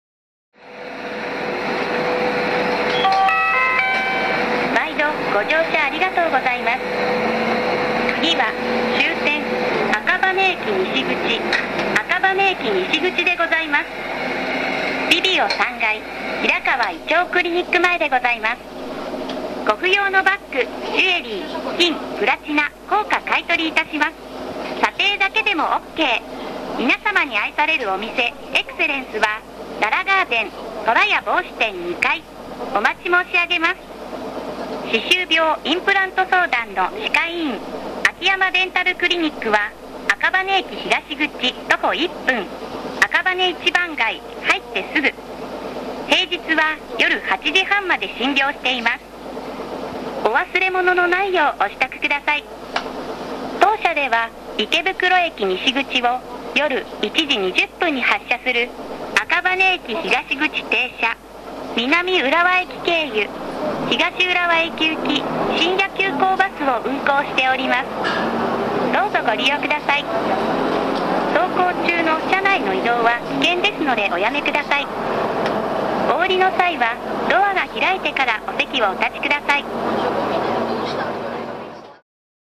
車内アナウンスコレクション
テープ時代からメロディーチャイムを使用しており何度かチャイムやアナウンサーが変わってきましたが、音声合成装置化してから一貫して同じチャイムを使用してます。